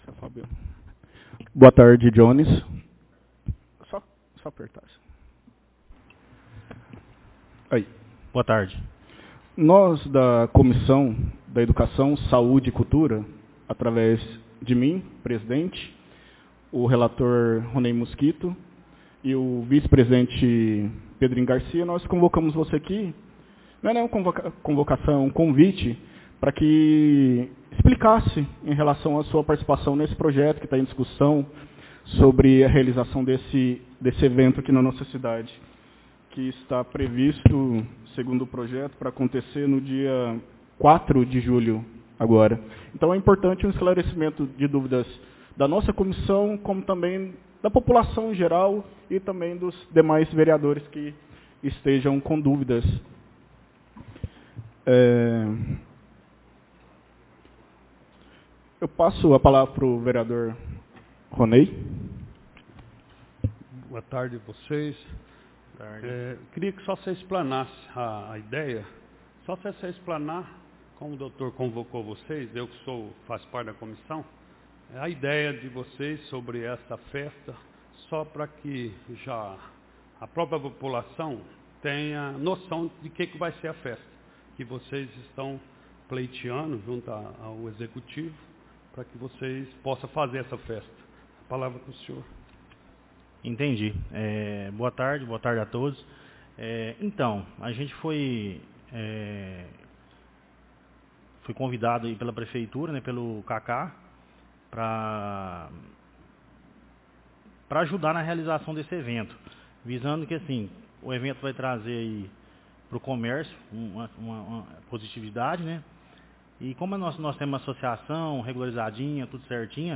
Reunião Comissão Saúde, Educação e Cultura - 22/04/2026